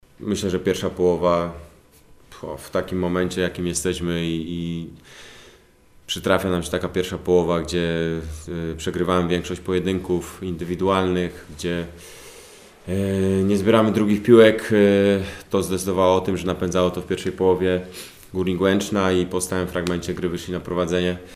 Powiedział po meczu trener GKS-u Tychy – Łukasz Piszczek.